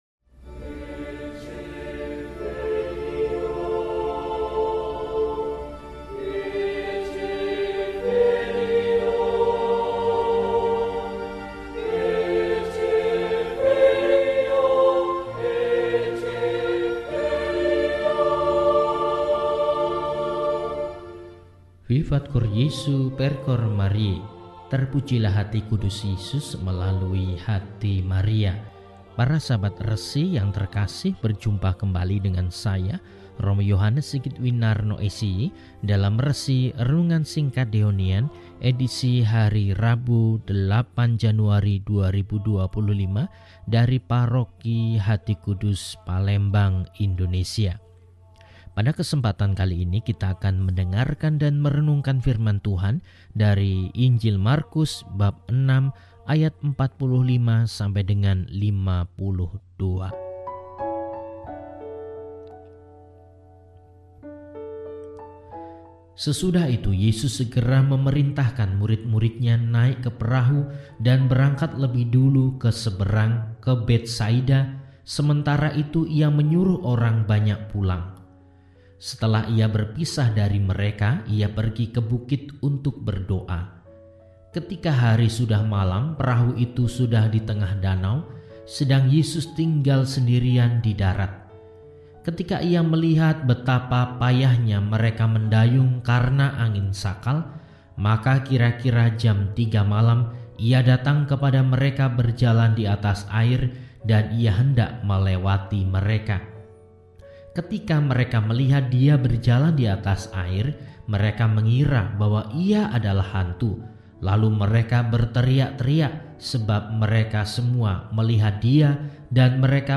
Rabu, 08 Januari 2025 – Hari Biasa Sesudah Penampakan Tuhan – RESI (Renungan Singkat) DEHONIAN